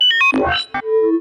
UIBeep_Tonal_Notification.wav